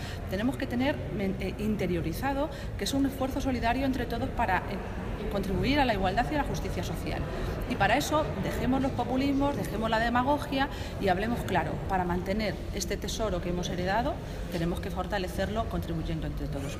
La portavoz socialista ha participado en una jornada sobre el sistema público de pensiones, organizada por UGT Castilla-La Mancha que ha tenido lugar en Albacete
Cortes de audio de la rueda de prensa